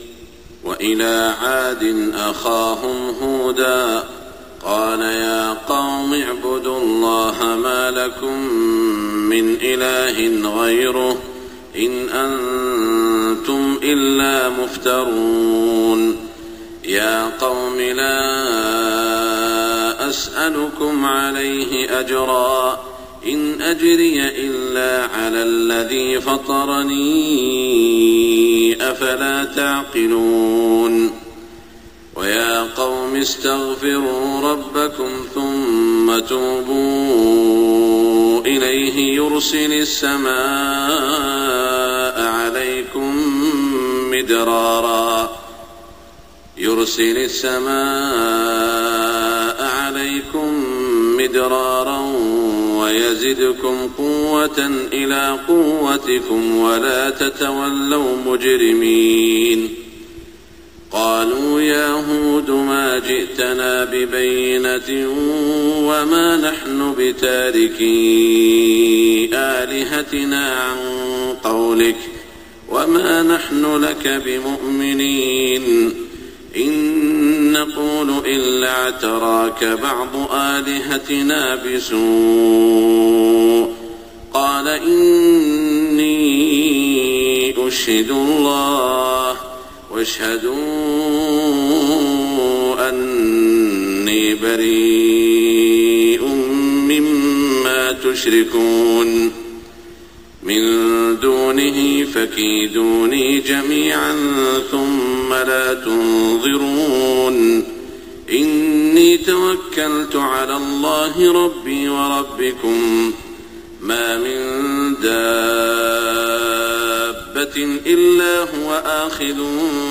صلاة الفجر 5-1426 من سورة هــود > 1426 🕋 > الفروض - تلاوات الحرمين